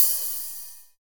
CLEAN OHH.wav